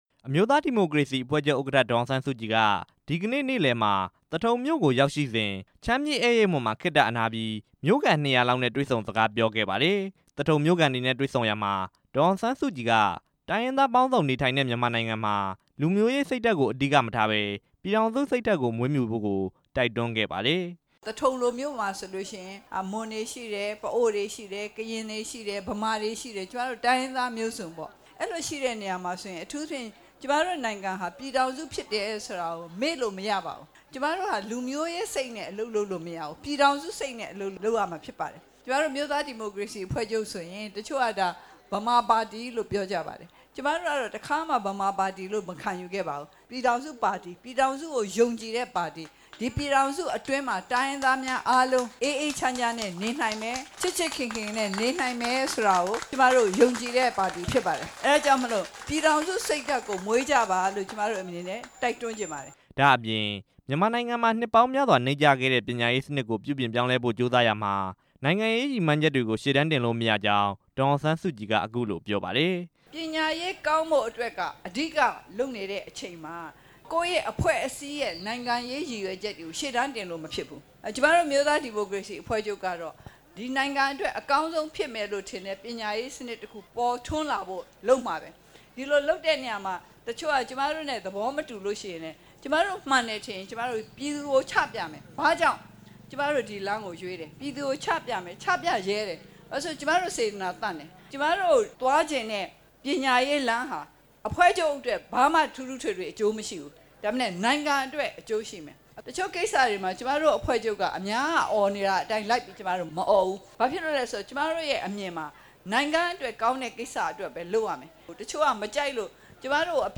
မွန်ပြည်နယ် မော်လမြိုင်မြို့၊ လှိုင်ရပ်ကွက် ကျောင်း အားကစားကွင်းမှာ ဒီနေ့ညနေပိုင်းက လူထုနဲ့တွေ့ဆုံပွဲမှာ အခုလိုပြောကြားလိုက်တာပါ။